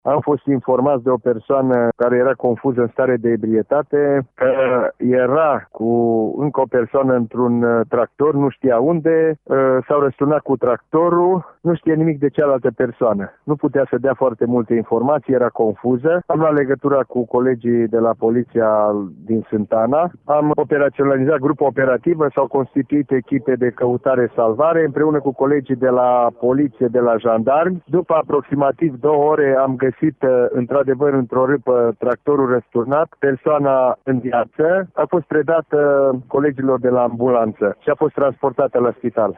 Şeful Inspectoratului pentru Situaţii de Urgenţă ”Horea” al judeţului Mureş, generalul Dorin Oltean: